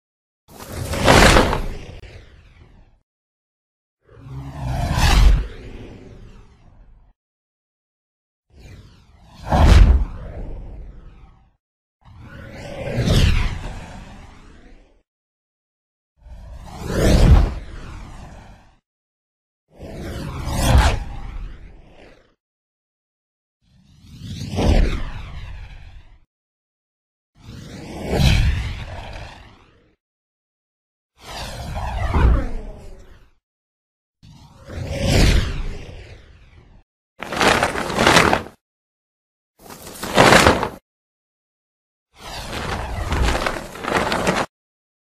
Звуки супергероя
Супергерои стремительно проносятся мимо, плащ развевается на ветру